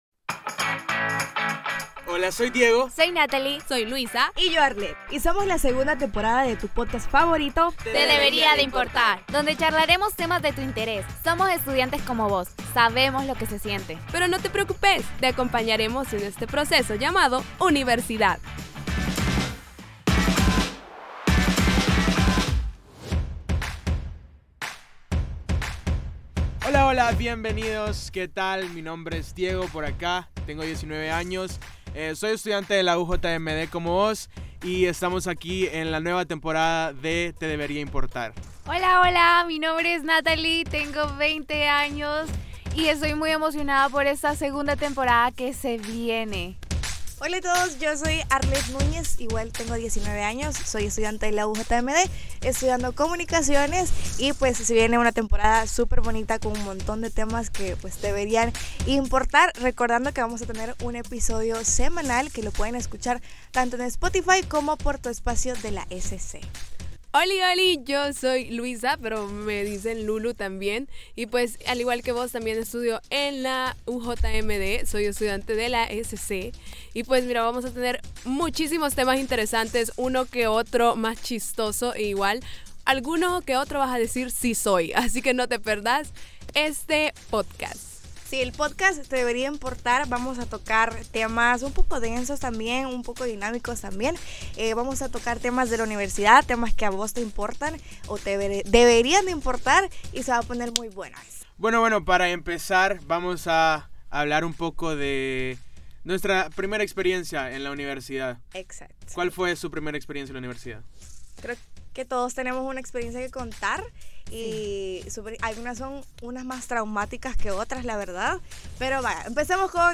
escuches como cuatro estudiantes como vos te llevan a vivir la vida universitaria desde otra perspectiva